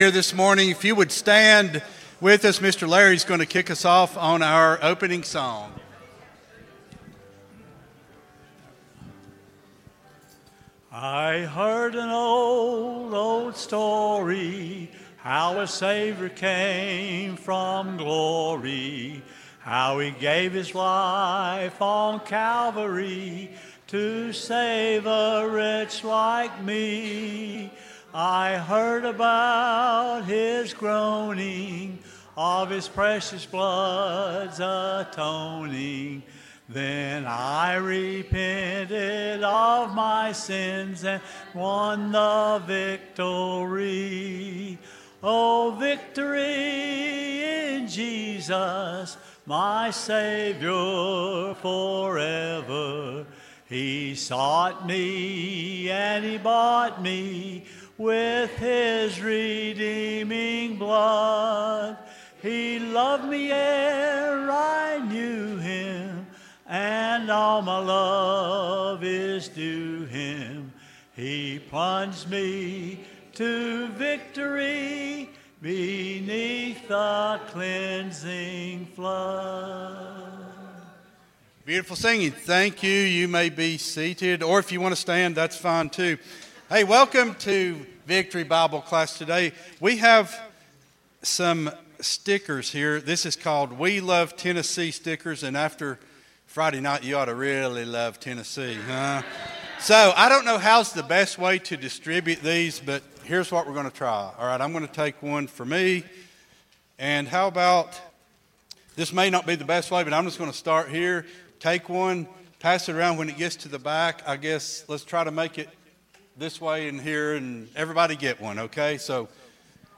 03-30-25 Sunday School Lesson | Buffalo Ridge Baptist Church